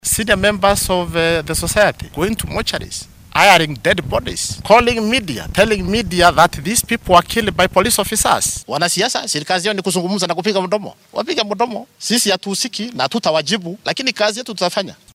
Arrintan ayuu Koome ka sheegay machadka lagu tababaro ciidamada booliiska ee Kiganjo ee ismaamulka Nyeri.